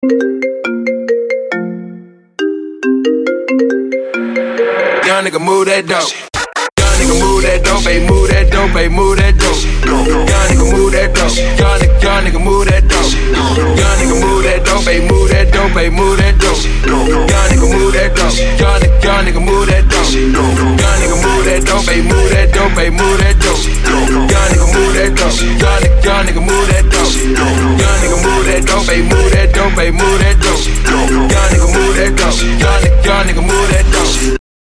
• Качество: 128, Stereo
Trap